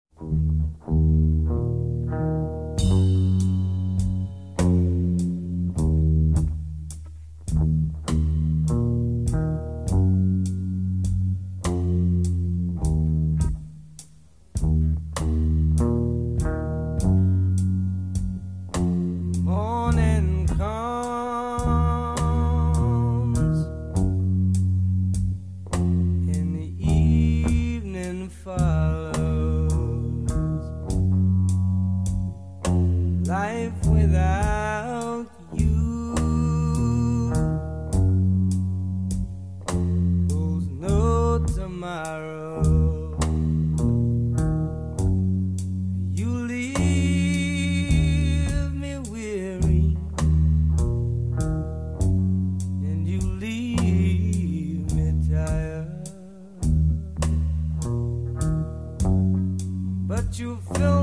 Рок
специализировавшихся на блюз-роке.